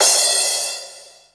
HR16B  CRASH.wav